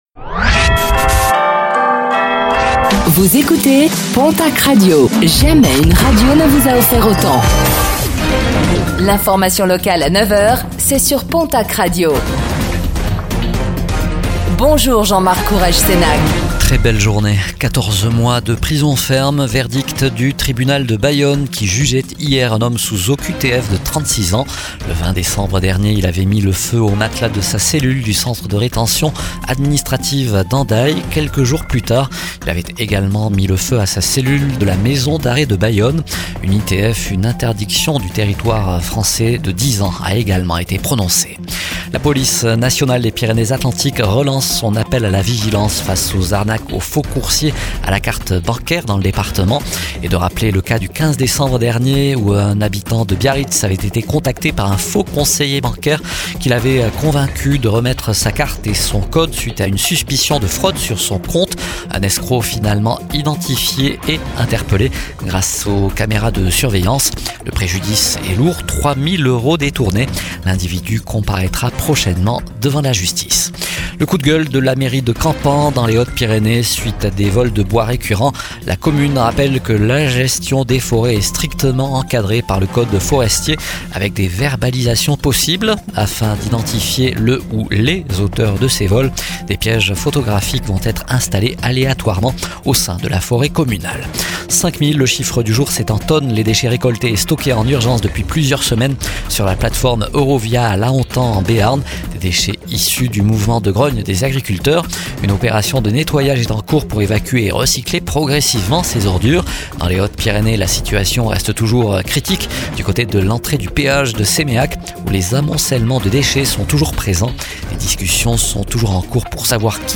Infos | Mardi 10 février 2026